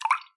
飞溅的火花 " 钓鳗鱼2
描述：在一个装满水的塑料实用水槽里做的溅射。没有应用混响，听起来像是在一个小房间里。最初是为了在戏剧中使用而录制的。
标签： 环境-声音的研究 钓鱼 飞溅 湿 液体
声道立体声